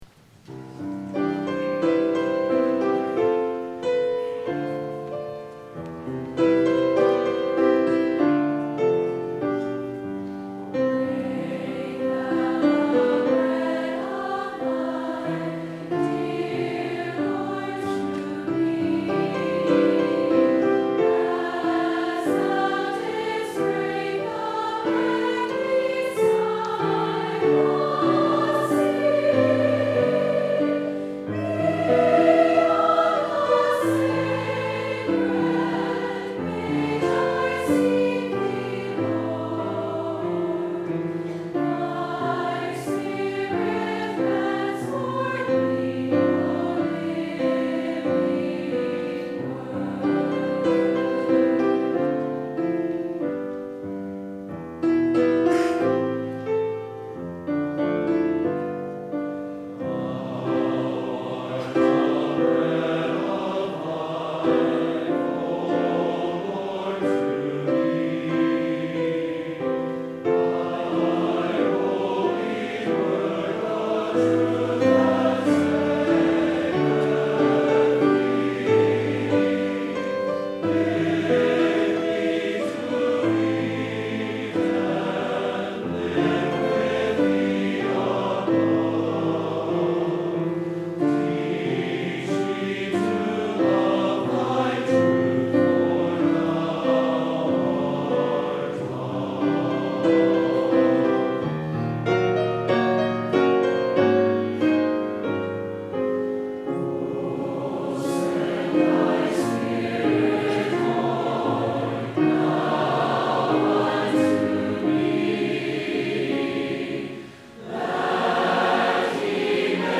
SATB and Piano
Choral
Anthem
Church Choir